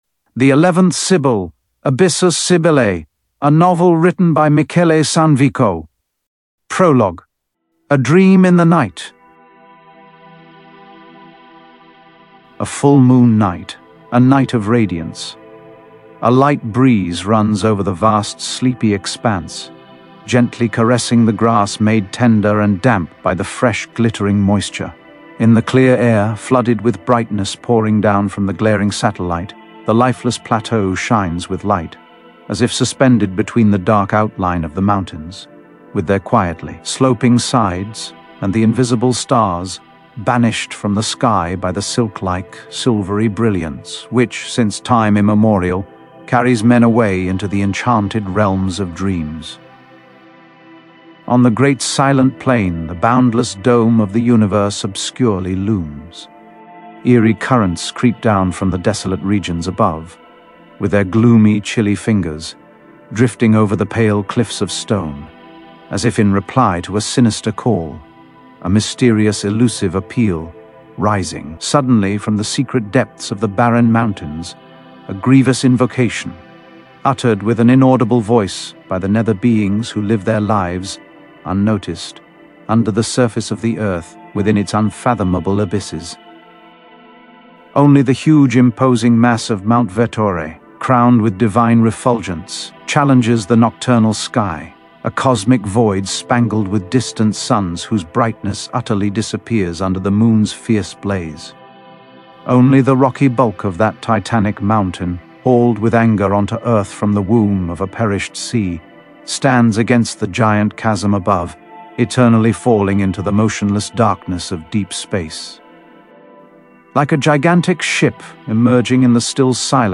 Ascolta il racconto del romanzo 'L'Undicesima Sibilla' narrato con voce meravigliosamente intensa e drammaticamente coinvolgente
Un audiolibro che racconta il testo di “L'Undicesima Sibilla” con una voce vibrante, profonda, drammatica, in grado di restituire appieno tutta l'inquietante emozione che promana da questo straordinario romanzo.
The Eleventh Sibyl - Audiobook (ENGLISH - Demo version - Prologue)